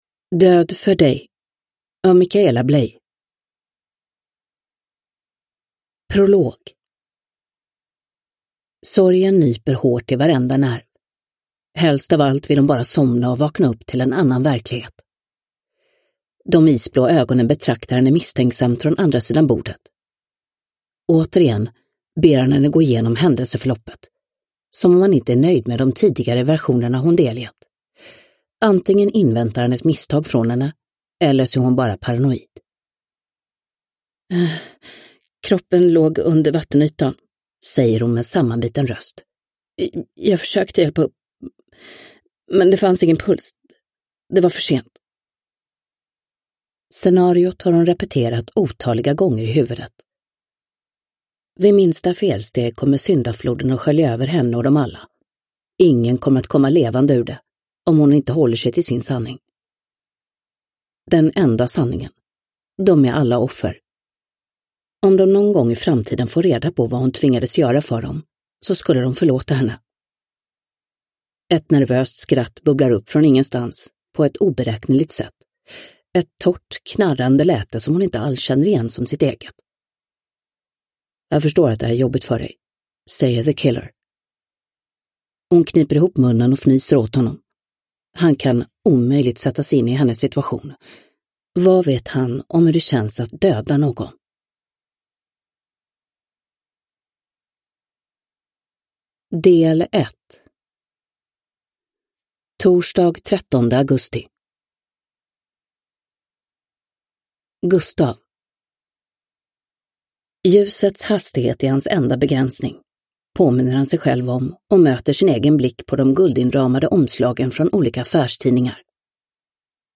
Ny inläsning av Mirja Turestedt
Uppläsare: Mirja Turestedt